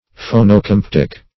Search Result for " phonocamptic" : The Collaborative International Dictionary of English v.0.48: Phonocamptic \Pho`no*camp"tic\, a. [Phono- + Gr. ka`mptein to bend: cf. F. phonocamptique.]
phonocamptic.mp3